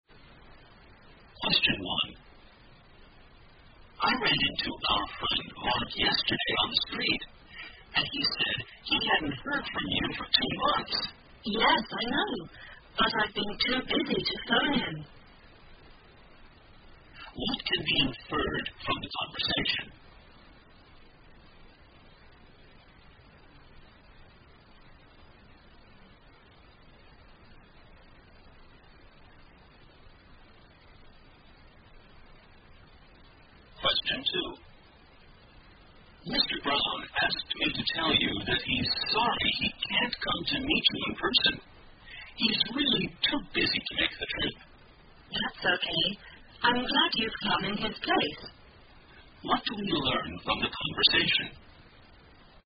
在线英语听力室084的听力文件下载,英语四级听力-短对话-在线英语听力室